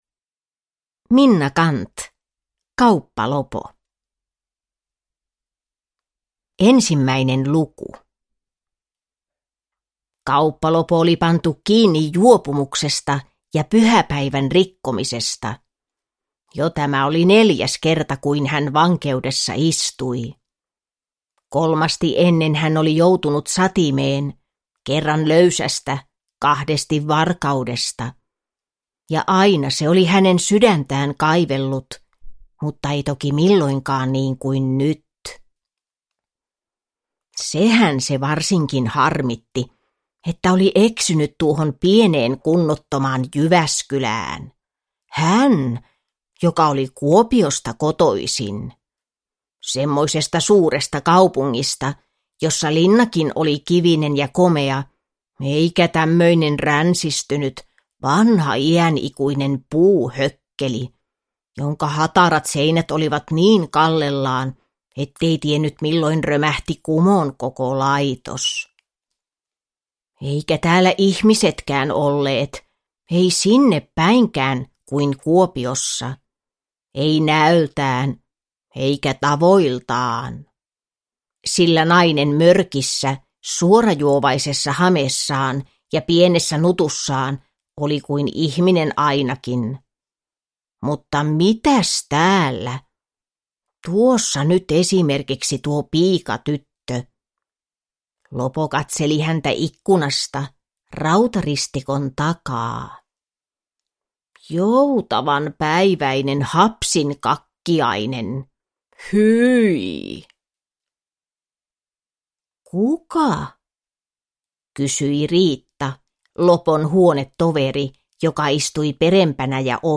Kauppa-Lopo – Ljudbok – Laddas ner